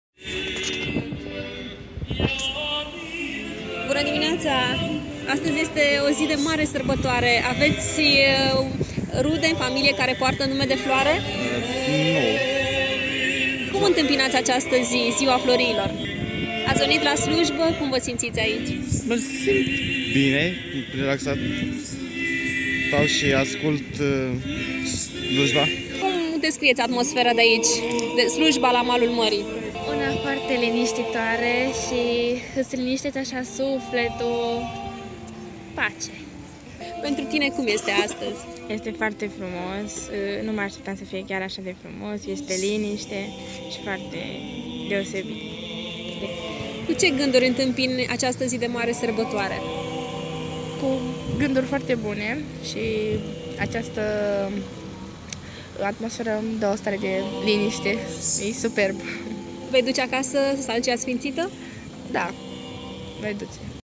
vox.mp3